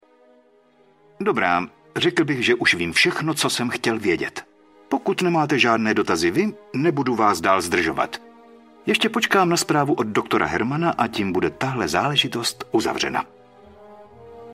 Zde máte oba hlasy, Miroslav Moravec tu postavu určitě nedabuje.